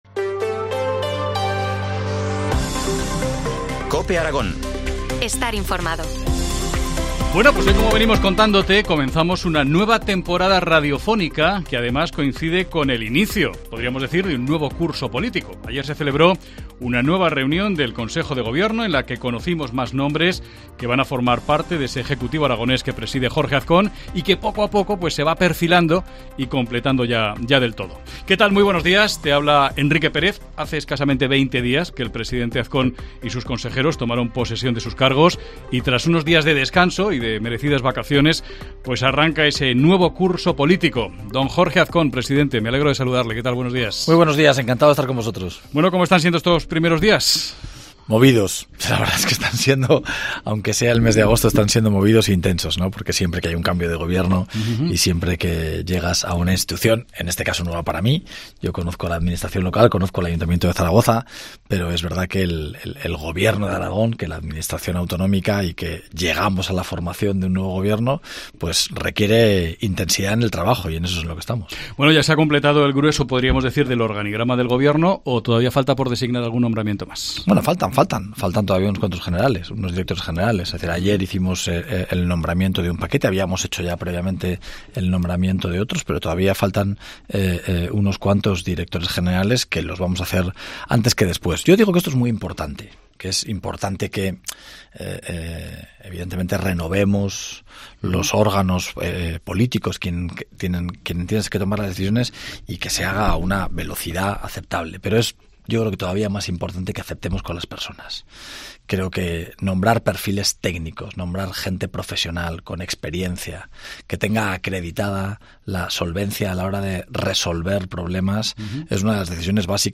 Primera entrevista del curso político al nuevo presidente de Aragón, Jorge Azcón.